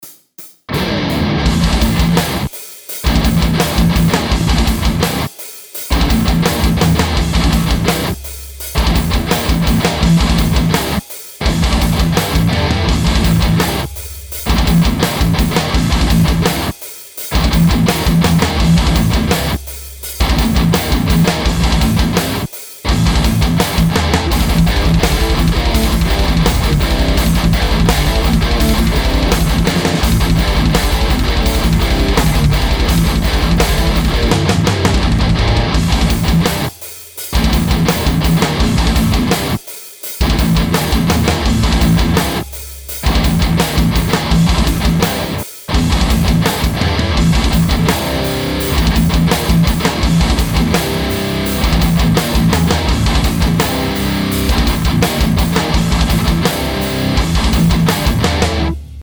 Ламповый преамп для записи - здравствуй ЗВУК!!
Вот тут уже собрал бошку (увеселитель полностью). Схема преампа - Энгл Е530 с некоторыми изменениями: